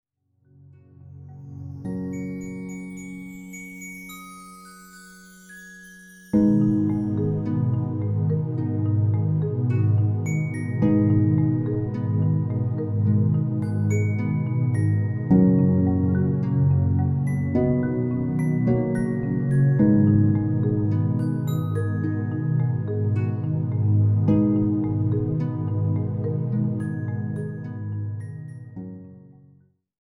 Lullaby covers